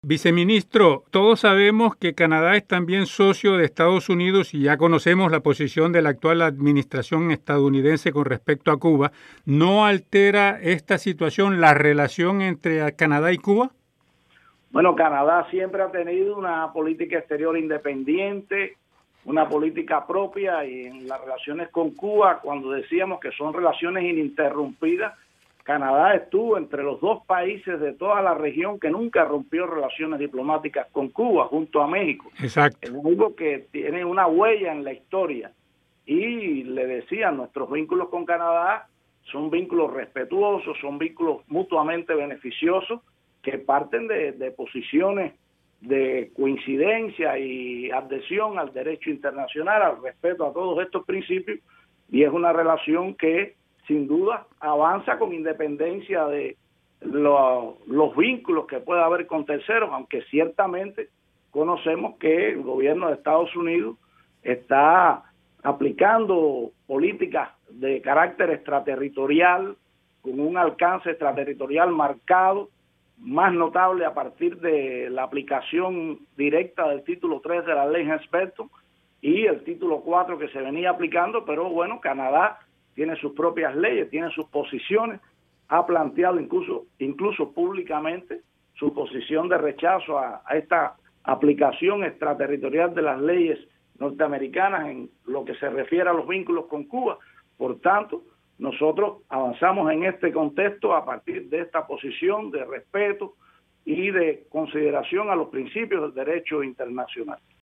Escuche la entrevista completa con Marcelino Medina González, Viceministro Primero de Relaciones Exteriores de Cuba.